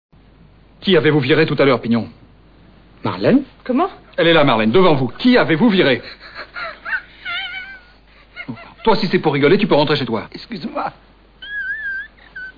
Rire 2 (Francis Huster)